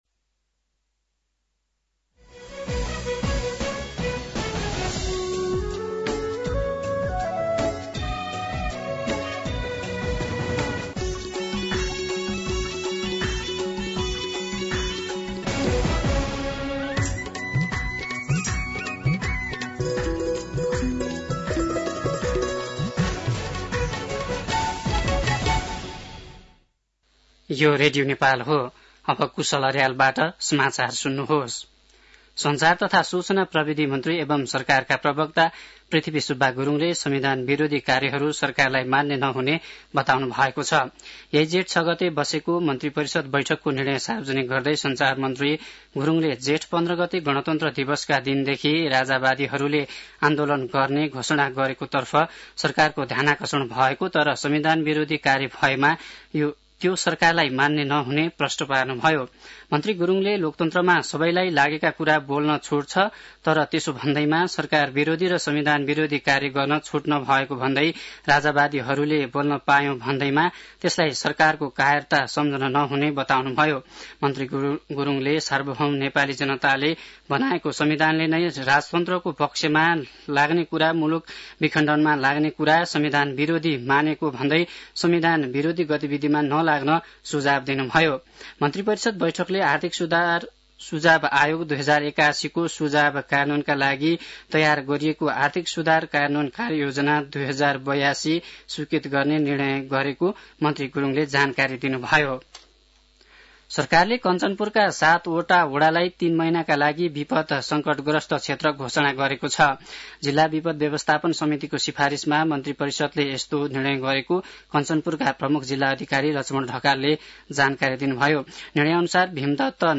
An online outlet of Nepal's national radio broadcaster
दिउँसो ४ बजेको नेपाली समाचार : ८ जेठ , २०८२